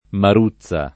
maruzza [ mar 2ZZ a ] s. f.